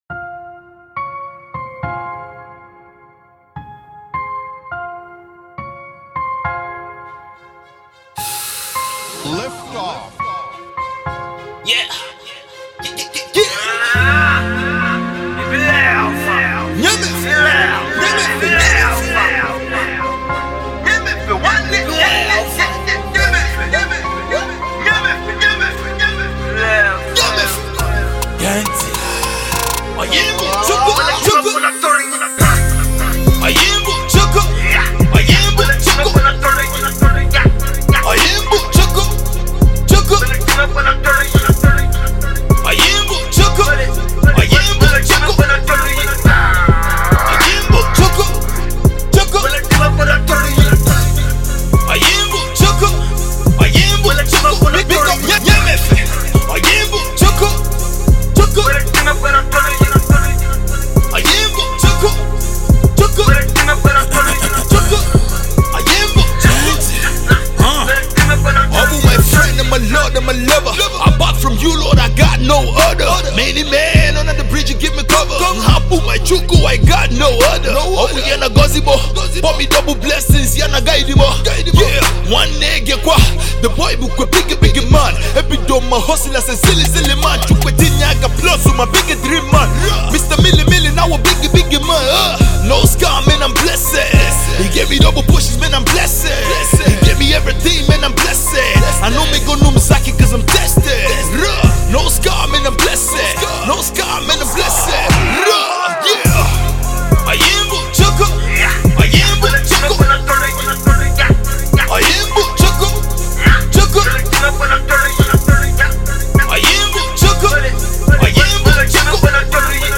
a producer and an indigenous rapper with spirit-filled vibes